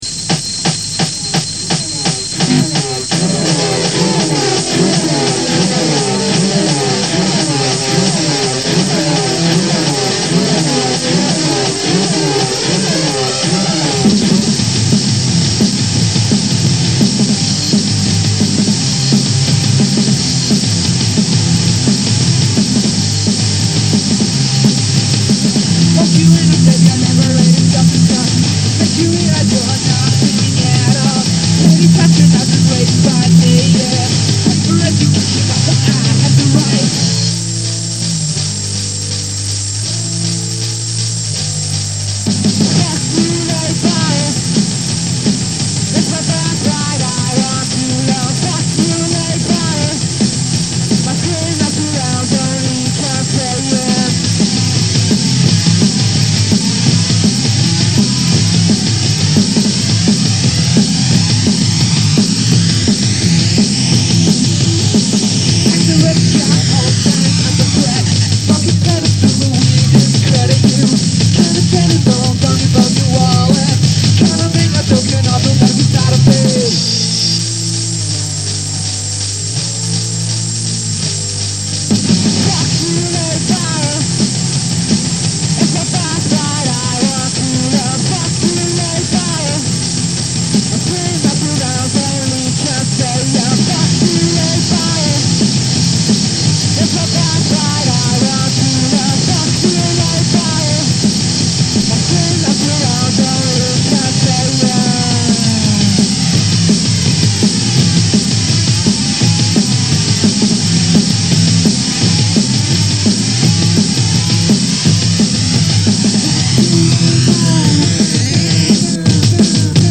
Vocals, Bass, Engineering
Guitar, Sampling, Loops, Engineering
Guitar, Effects, Noises
Synth Drums, Programming